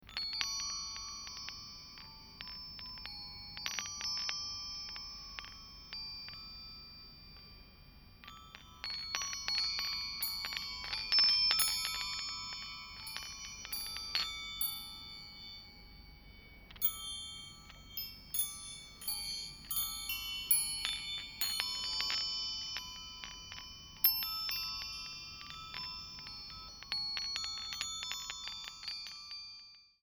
Blue Agate Chimes
Size: 18″ Long